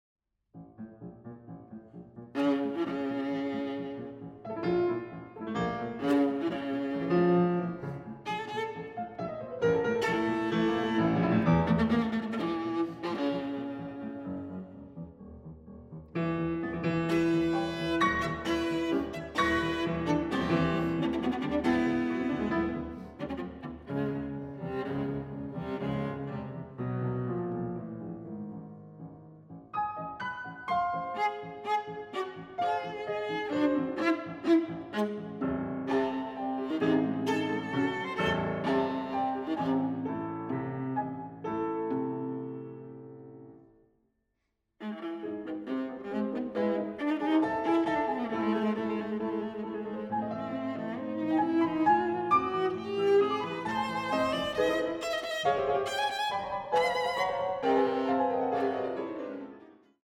Viola
Piano
Allegro ironico